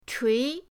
chui2.mp3